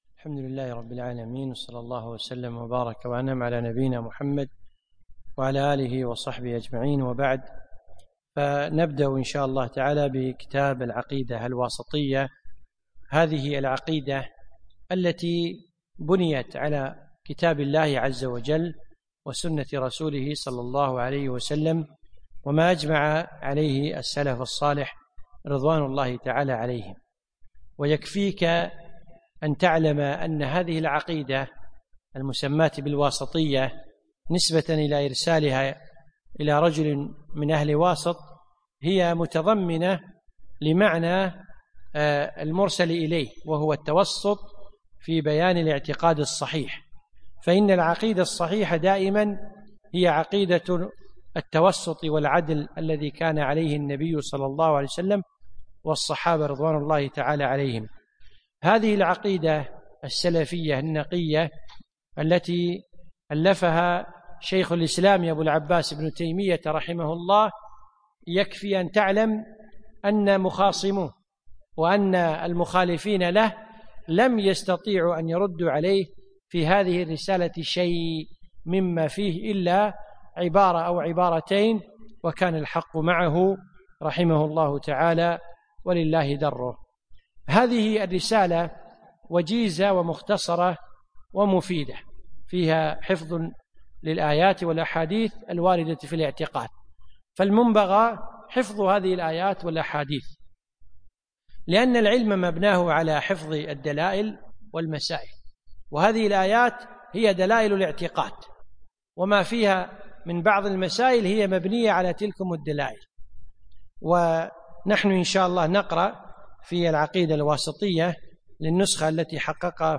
يوم السبت 5 جمادى ثاني 1438 الموافق 4 3 2017 في مسجد عائشة المحري المسايل